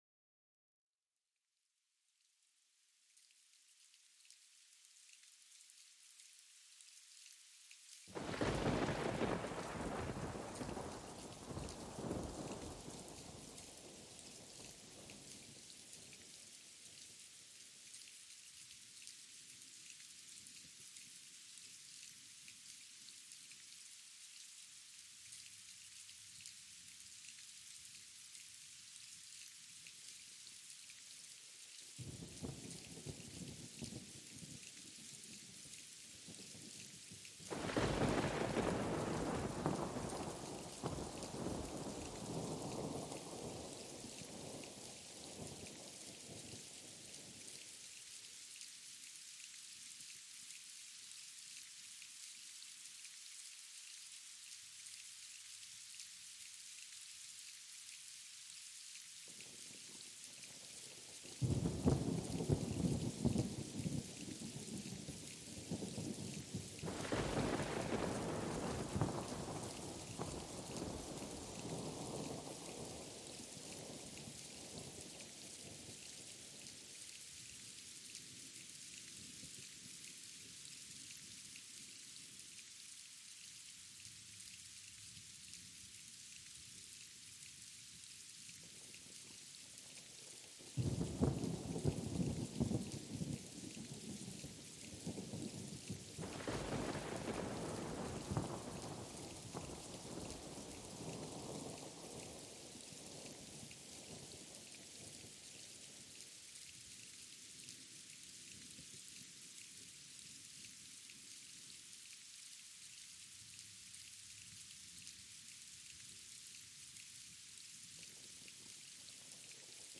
Experimenta el retumbar cautivador del trueno y los impresionantes destellos de relámpagos. Sumérgete en el ritmo relajante de la lluvia intensa.